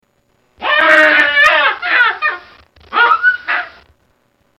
Baboon
Tags: Science & Nature Animals Egypt Animals of Egypt Ancient Egypt